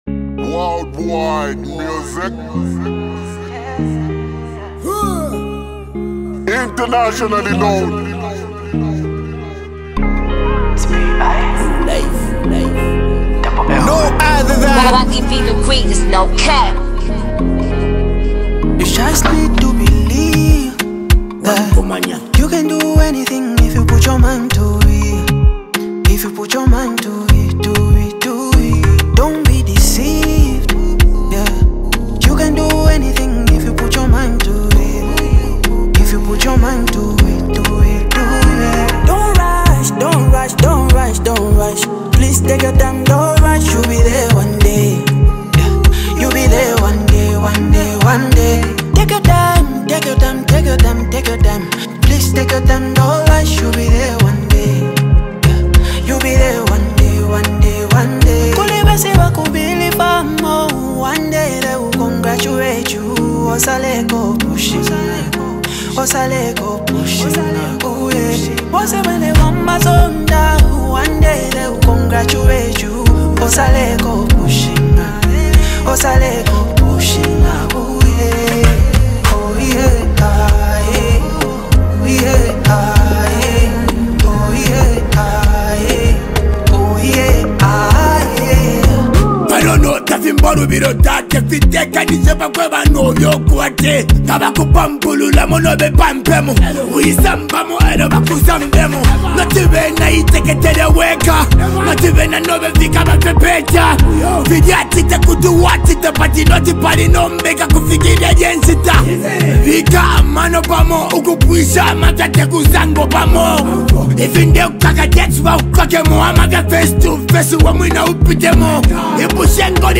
A Street Anthem Worth the Hype
blends hip-hop with Afro-fusion elements